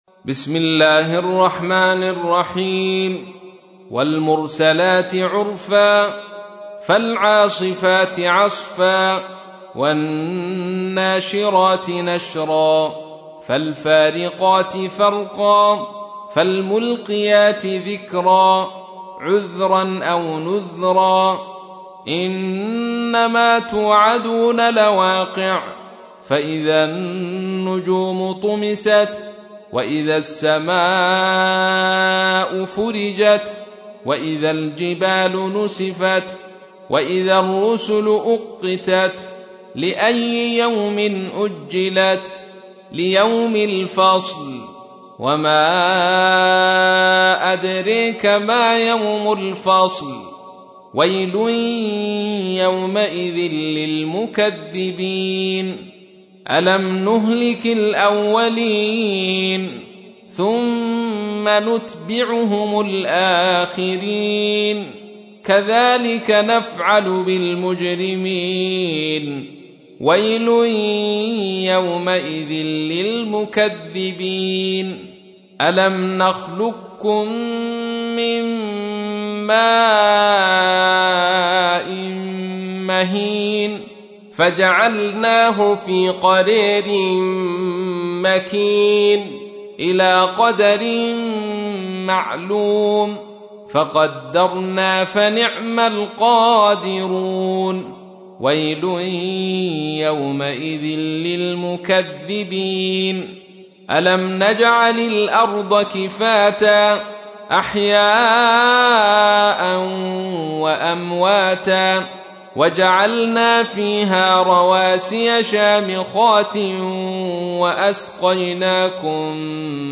تحميل : 77. سورة المرسلات / القارئ عبد الرشيد صوفي / القرآن الكريم / موقع يا حسين